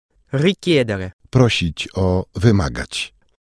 - słuchając nagrań native speakerów, nauczysz się prawidłowej wymowy
Możesz posłuchać nie tylko słówek wraz z polskimi tłumaczeniami, ale też przykładowych zdań w wykonaniu profesjonalnych lektorów.
Przykładowe słówko